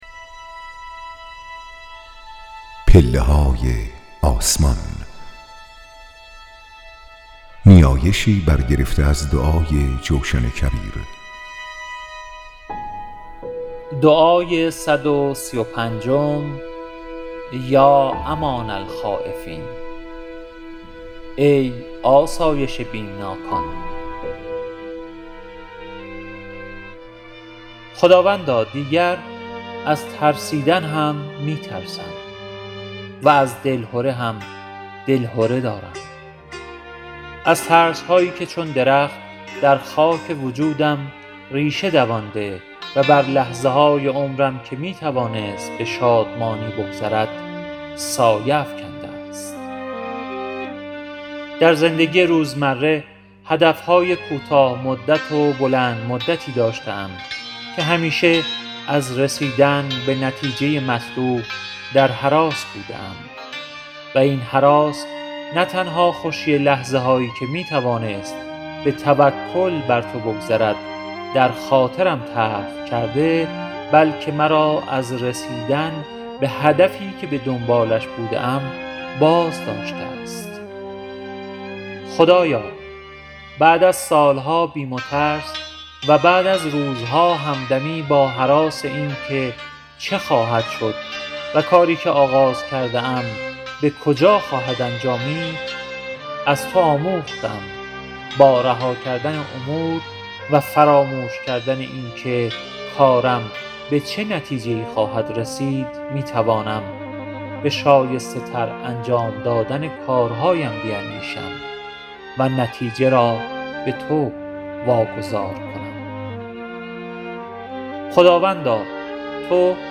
لینک خرید کتاب گویای پله‌های آسمان در فیدیبو